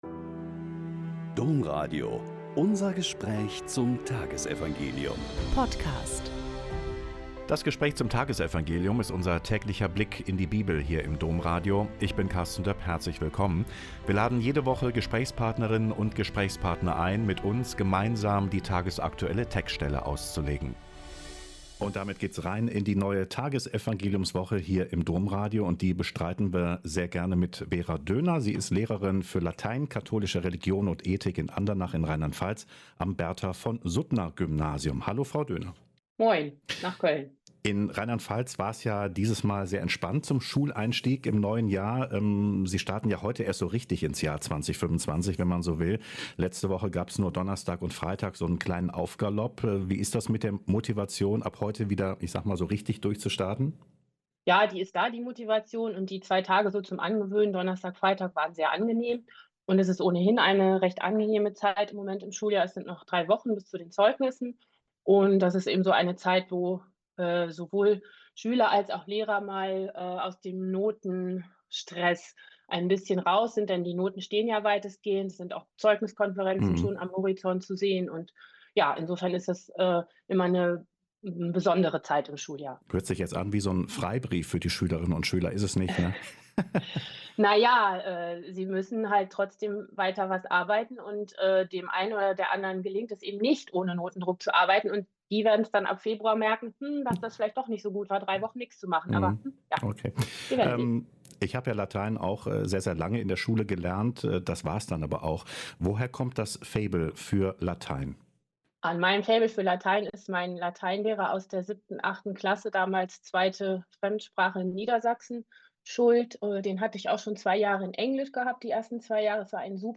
Mk 1,14-20 - Gespräch